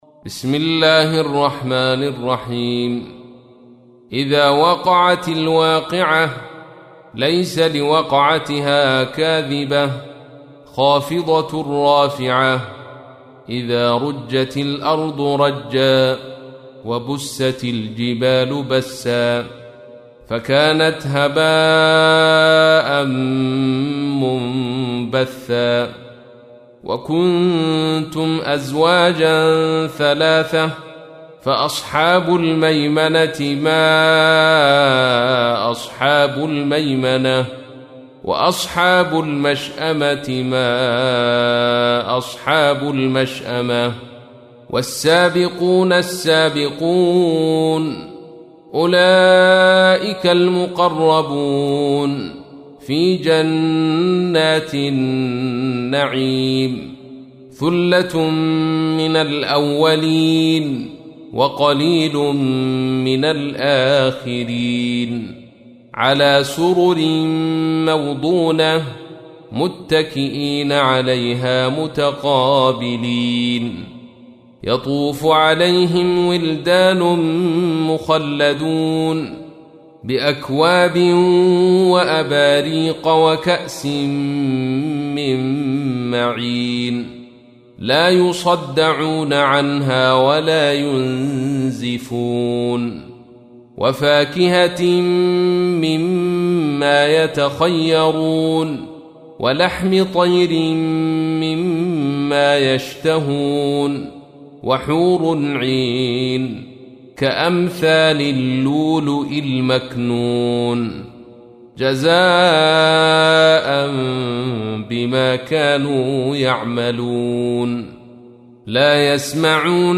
تحميل : 56. سورة الواقعة / القارئ عبد الرشيد صوفي / القرآن الكريم / موقع يا حسين